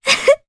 Juno-Vox_Happy1_jp.wav